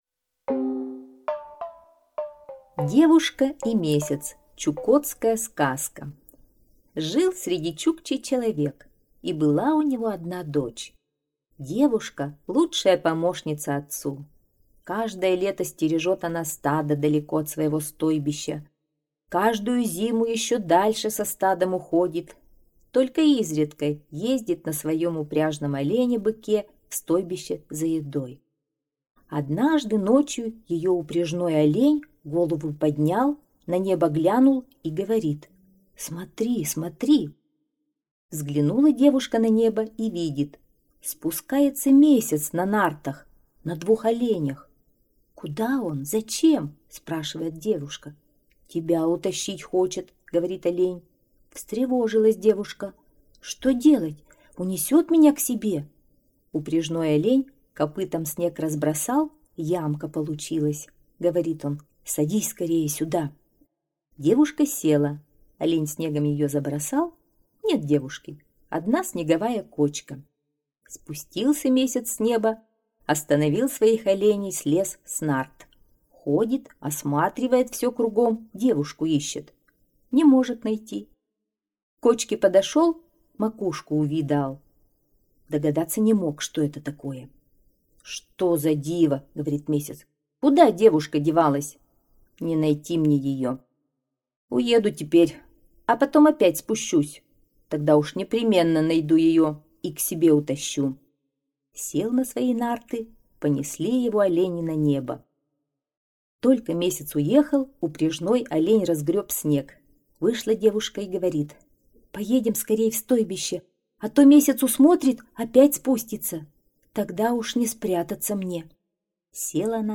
Девушка и месяц - чукотская аудиосказка - слушать онлайн